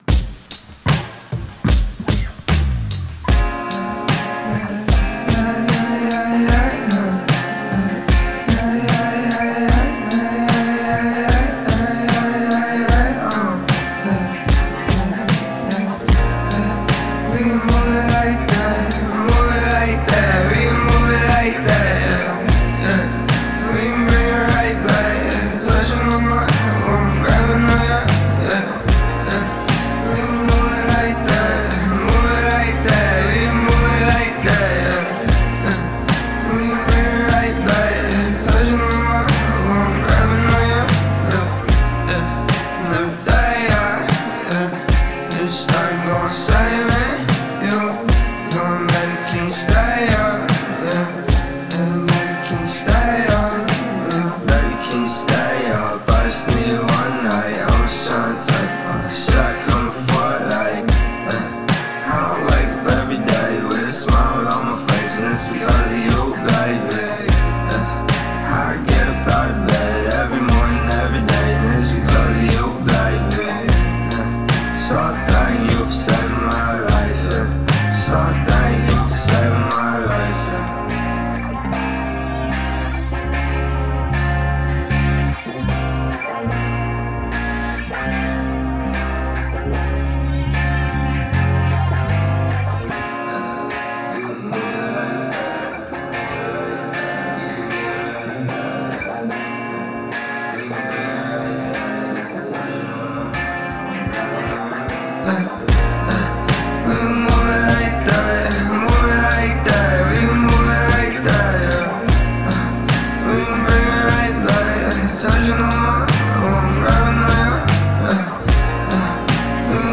(outro guitar solo added)